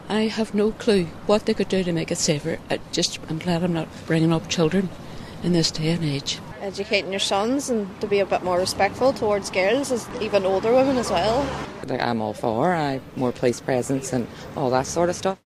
These women in the city were asked what they believe would make the city safer…………….